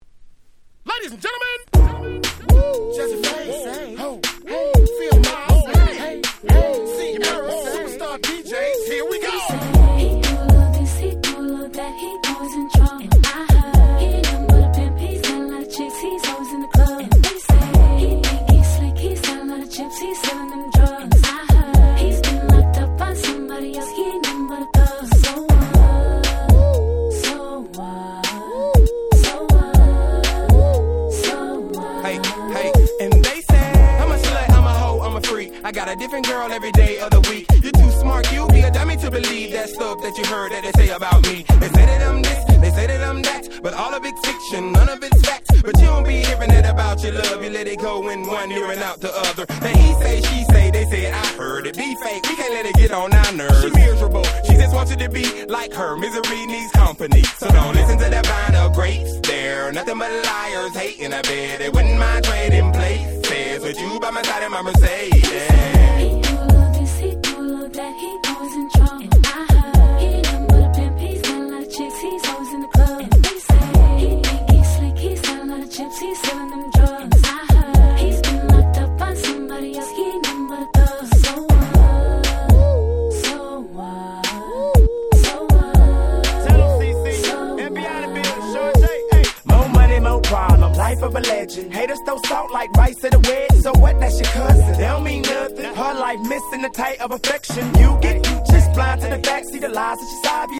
06' Super Hit Southern Hip Hop !!